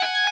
guitar_025.ogg